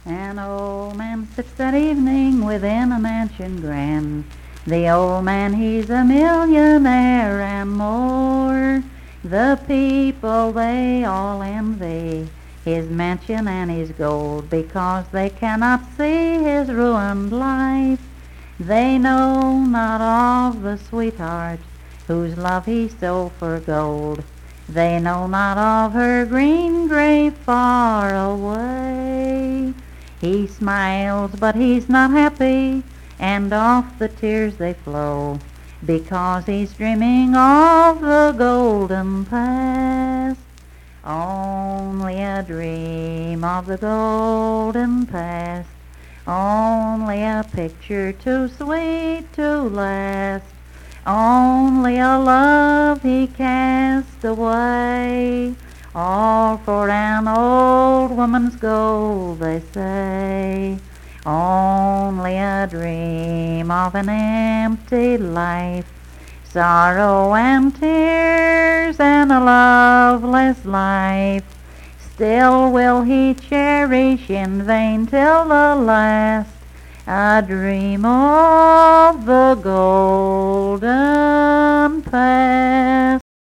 Unaccompanied vocal music performance
Verse-refrain 1(8) & R(8).
Voice (sung)